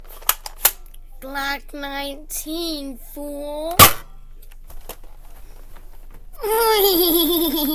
Glock 19: Instant Play Sound Effect Button